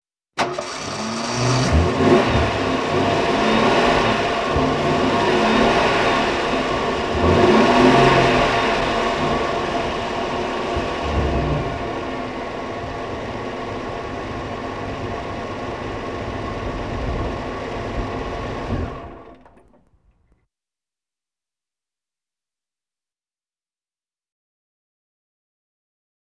Car Start Sound Effect
Download a high-quality car start sound effect.
car-start.wav